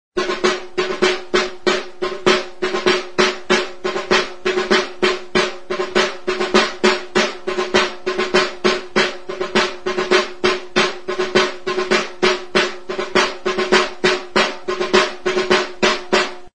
Music instrumentsDANBOLINA
Membranophones -> Beaten -> Stick-beaten drums
Larruzko bi mintz ditu, tenkatzeko sokazko sistema, larruzko tensoreak eta bordoia atzeko mintzean, hala nola besotik zintzilikatzeko soka.